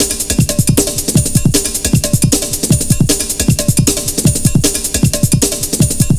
Index of /90_sSampleCDs/Zero-G - Total Drum Bass/Drumloops - 1/track 03 (155bpm)